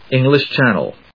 アクセントÉnglish Chánnel 発音を聞く